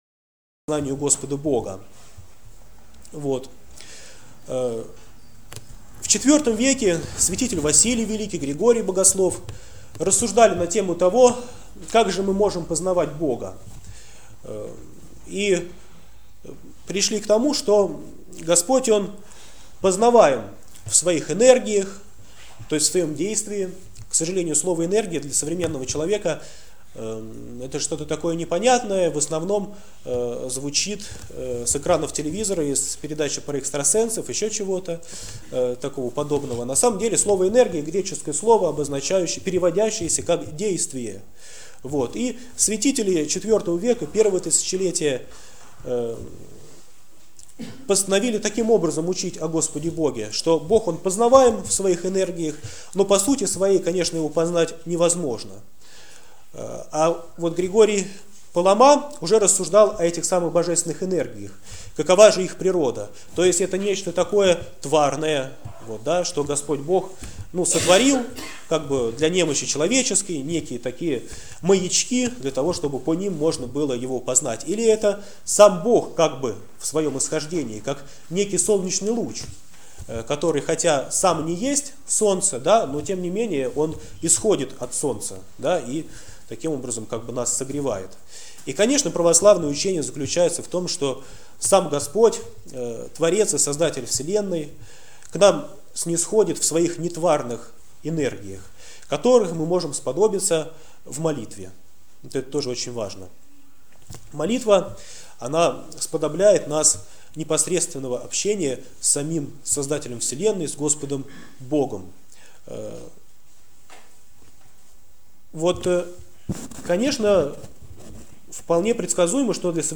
Проповедь в Неделю 2-ю Великого Поста